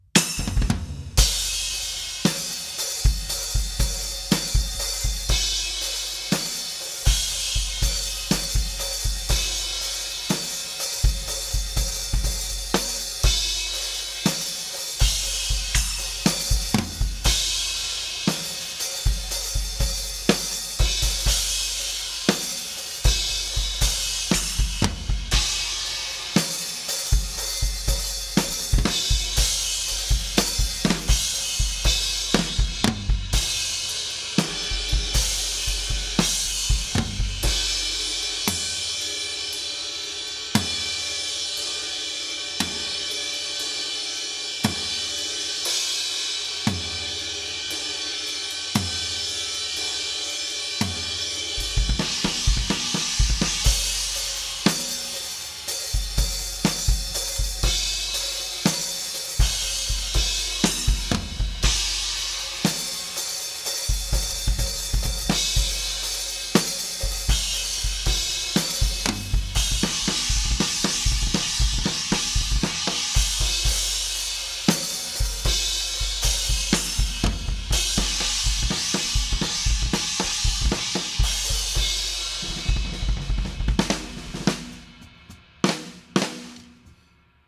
(Note to self, an iPhone 5’s built-in microphone isn’t very capable of recording drums…)
Also noteworthy, I did *not* intend to tune the toms to any certain notes or intervals. It just so happens that this was the end result when tuning each drum individually in hopes of making each one sing as much as possible.